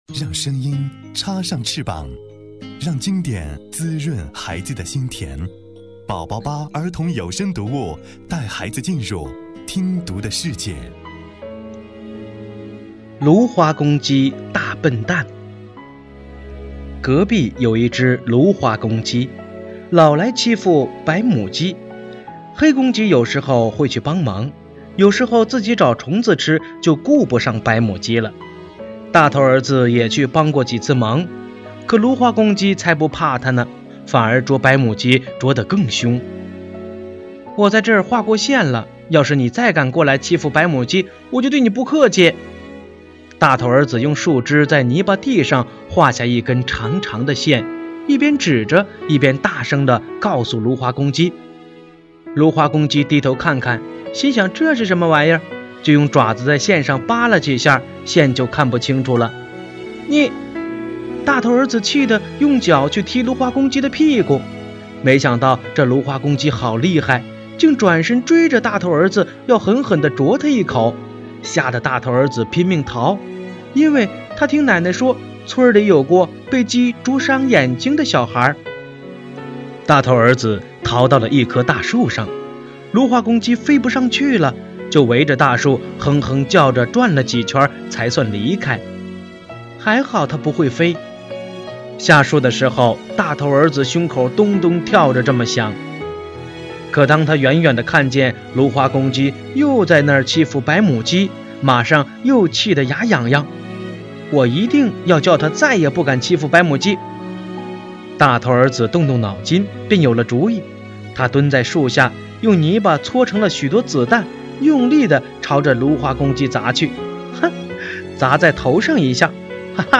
首页>mp3 > 儿童故事 > 05芦花公鸡大笨蛋（大头儿子和公鸡母鸡）